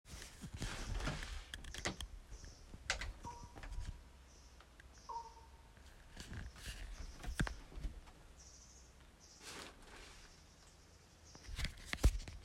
Fugl som høres ut som en sonar
Det hørtes ut som den var et godt stykke unna (100m) så jeg antar det må ha vært en større fugl. Fikk med meg 2 «gakk» på opptaket, så tenkte først det måtte være noe slags høns.
Dette er klonkekyder fra en ravn.